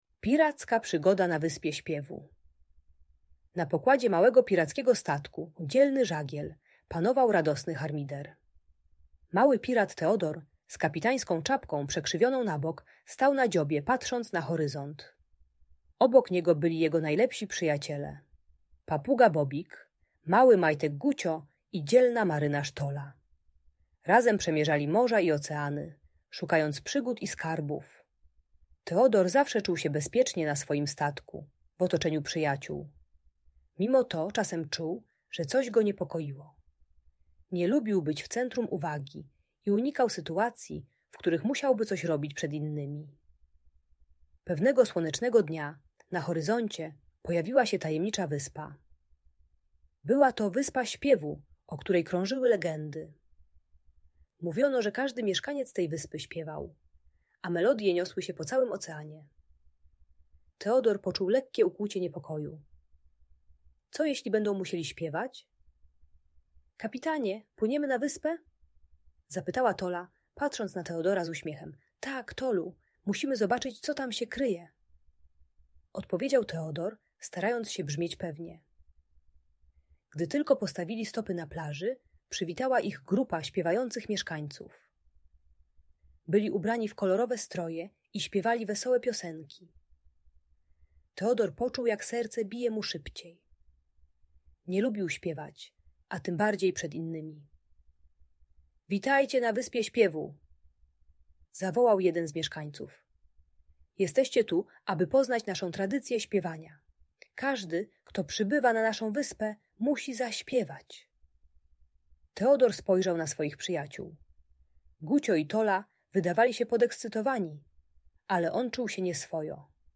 Piracka Przygoda na Wyspie Śpiewu - Lęk wycofanie | Audiobajka